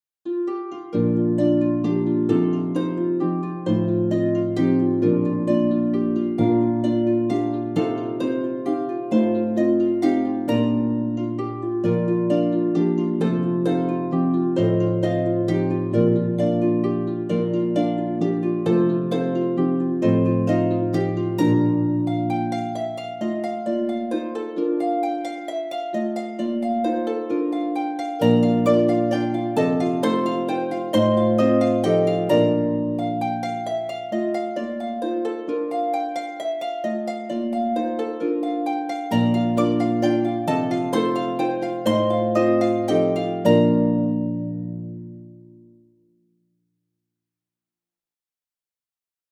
for four lever or pedal harps